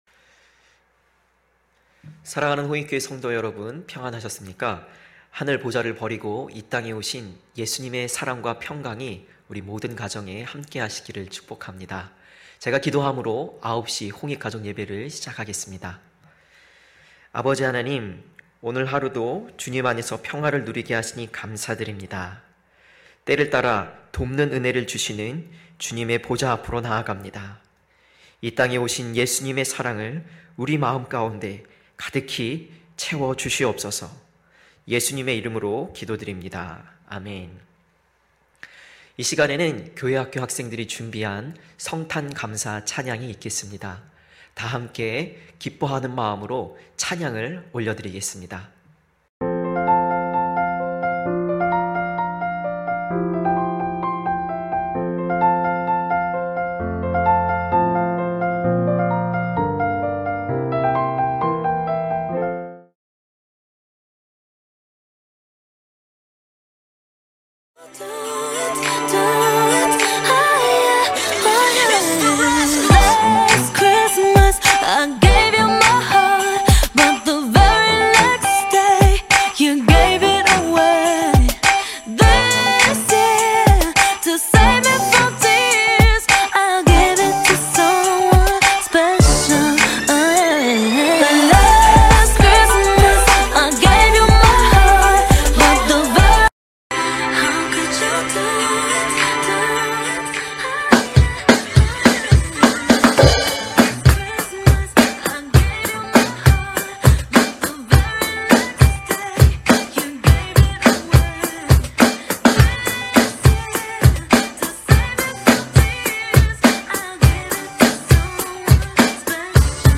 9시 홍익가족예배 성탄(12월22일).mp3